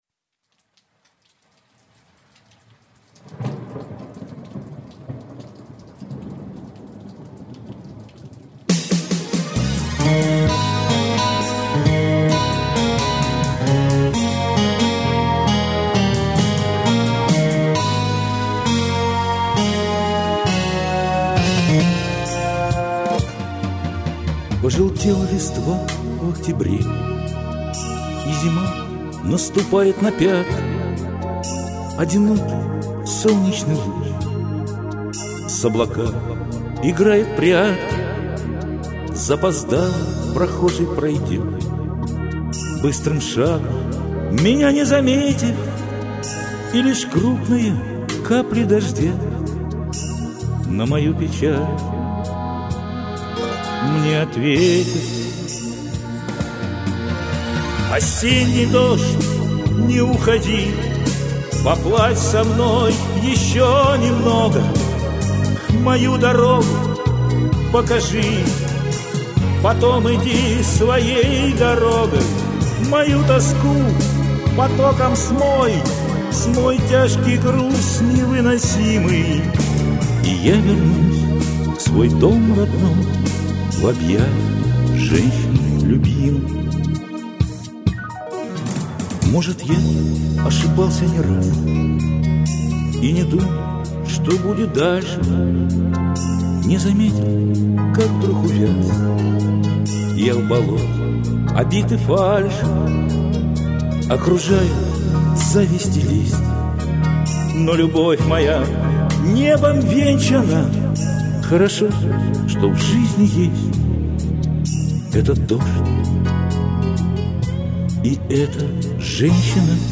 Про ревер уже написали.